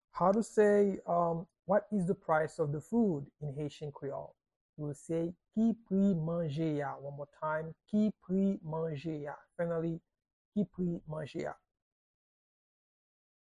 Pronunciation and Transcript:
How-to-say-Whats-the-price-of-the-food-in-Haitian-Creole-–-Ki-pri-manje-a-pronunciation.mp3